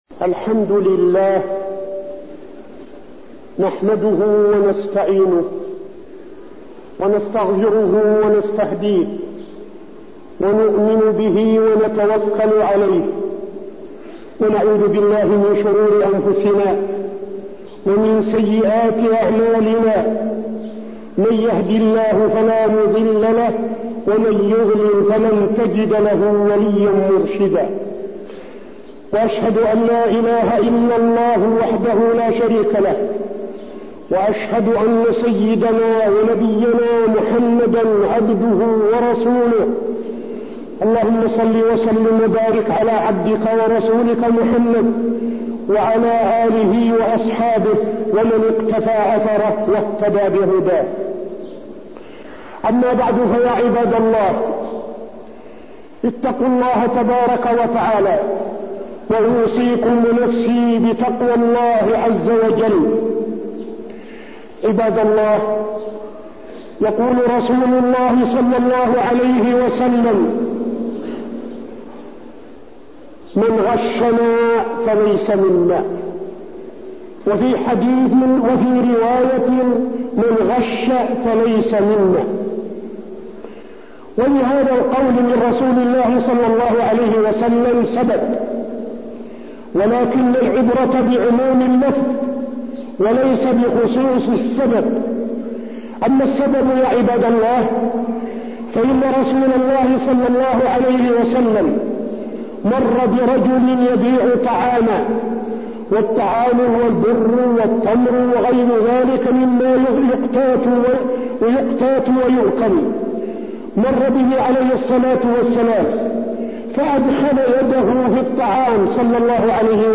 تاريخ النشر ٢٣ جمادى الأولى ١٤٠٧ هـ المكان: المسجد النبوي الشيخ: فضيلة الشيخ عبدالعزيز بن صالح فضيلة الشيخ عبدالعزيز بن صالح من غشنا فليس منا